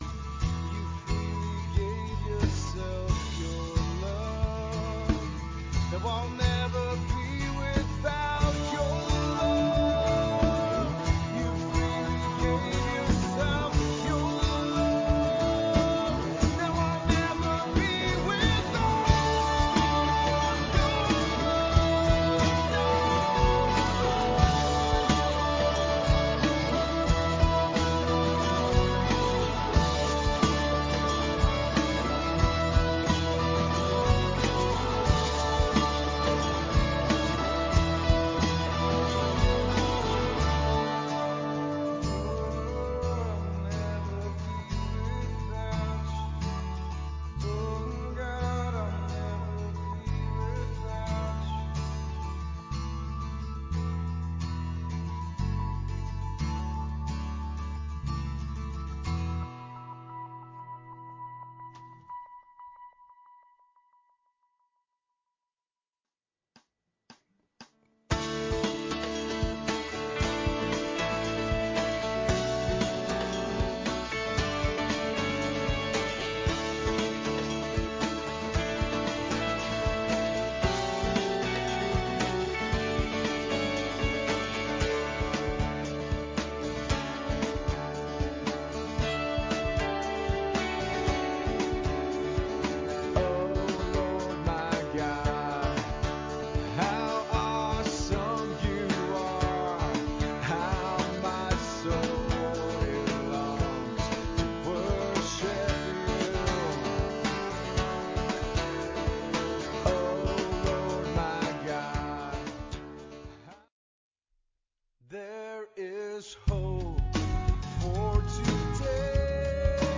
LISTEN (church service)